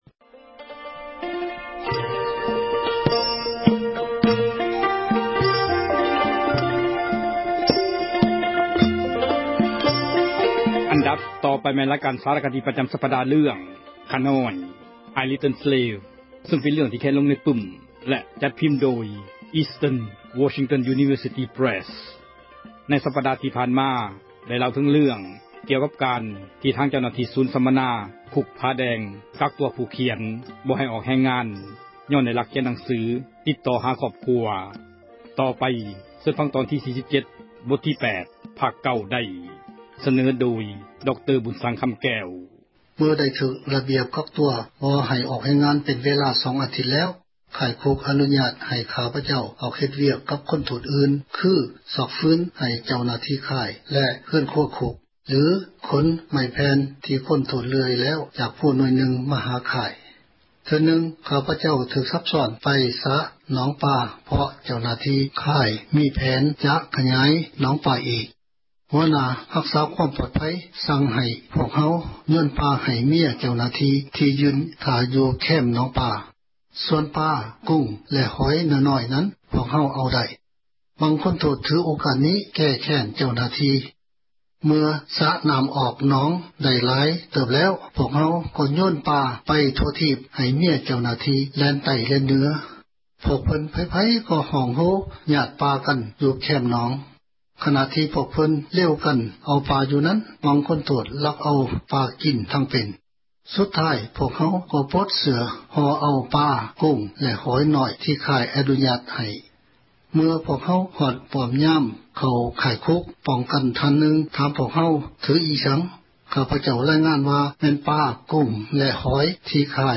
ສາຣະຄະດີ ເຣື່ອງ ”ຂ້ານ້ອຍ" (I Little Slave) ພາຄ 47 ສເນີໂດຍ